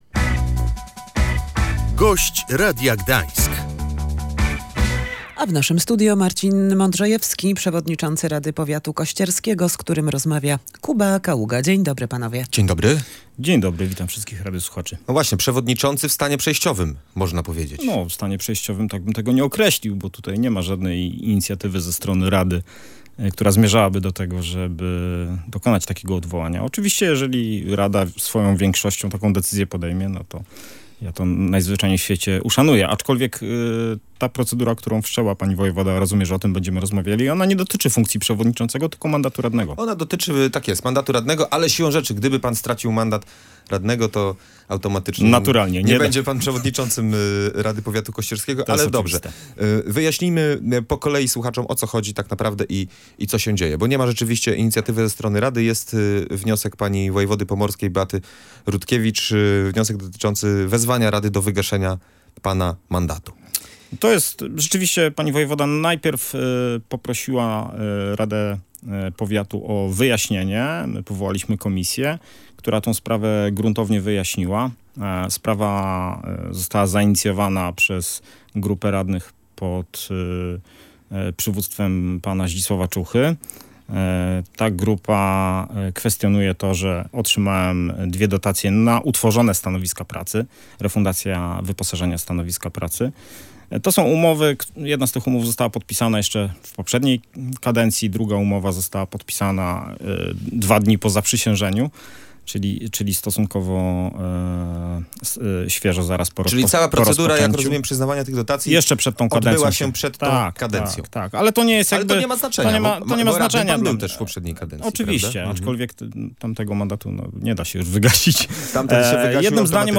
Jak mówił w Radiu Gdańsk Marcin Modrzejewski, przepis jest traktowany przez wojewodę zbyt szeroko.